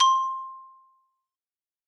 Xylo_Medium_C5_ff_01_far.wav